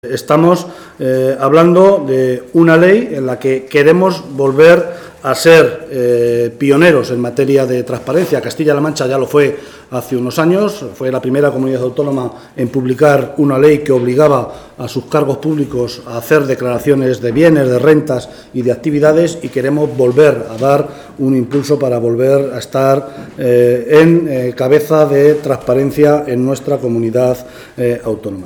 Comparecencia Cortes Martínez Guijarro: transparencia